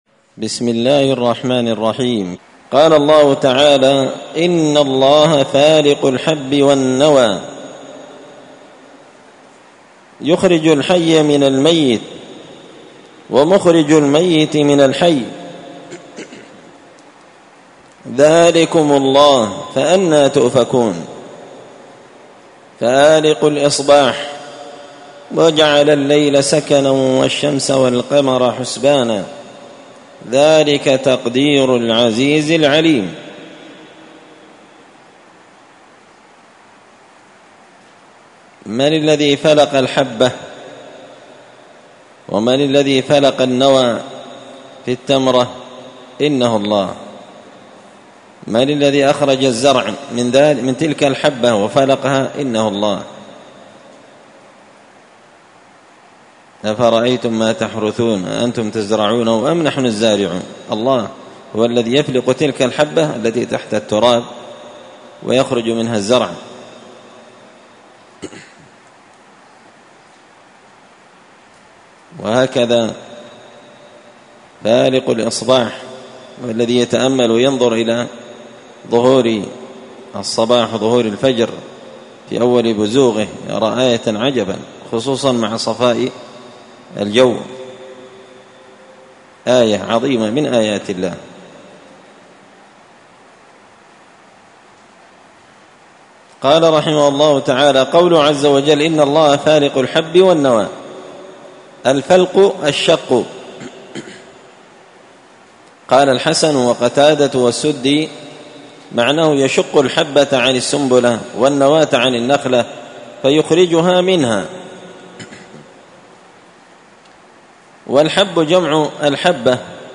مسجد الفرقان قشن_المهرة_اليمن
مختصر تفسير الإمام البغوي رحمه الله الدرس 332